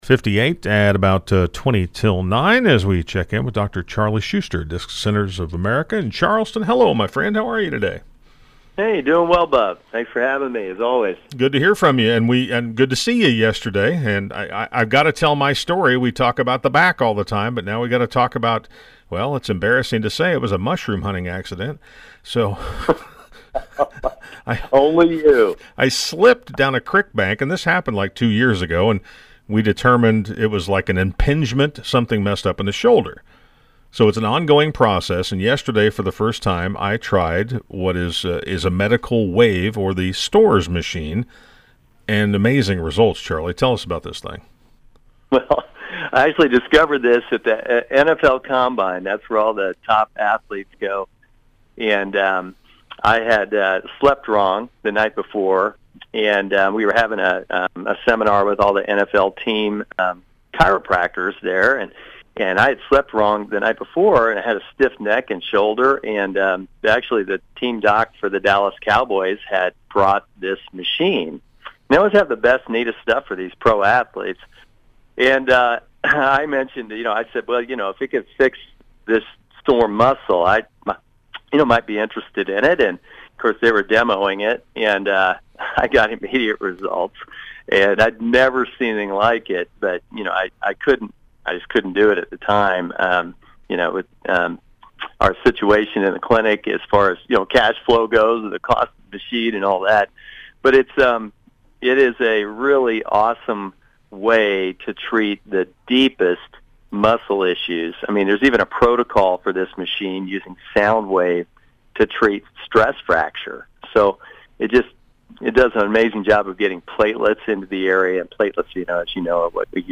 by Radio Interview